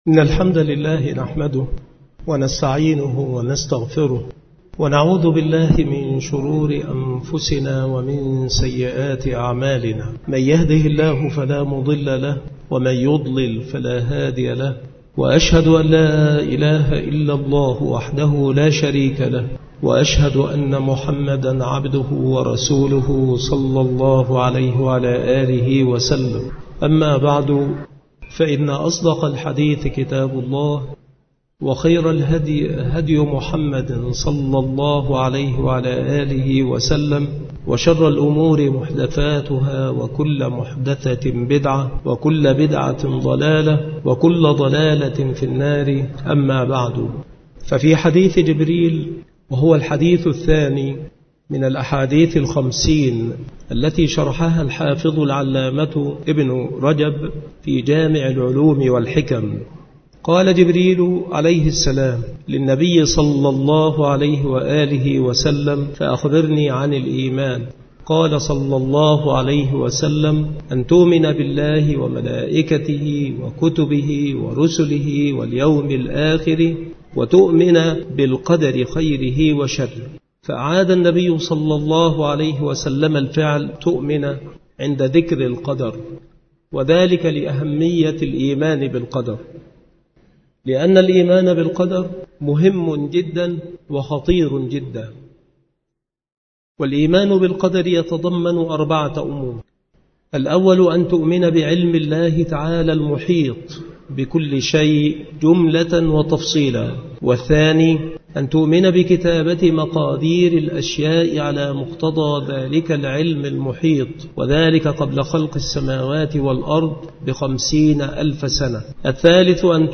شروح الحديث
مكان إلقاء هذه المحاضرة بالمسجد الشرقي بسبك الأحد - أشمون - محافظة المنوفية - مصر